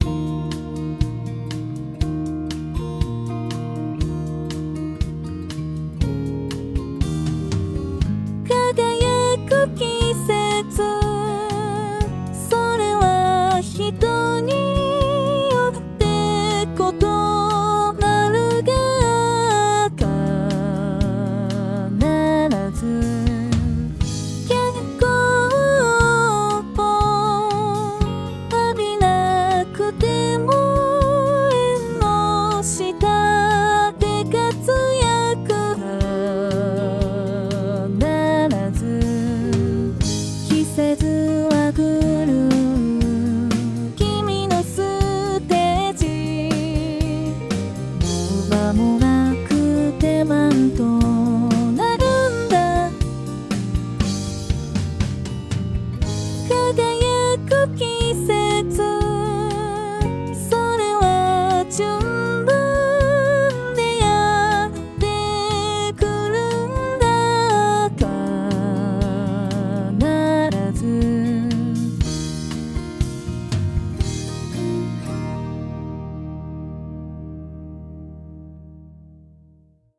No.01211 [歌]
※Band-in-a-Boxによる自動作曲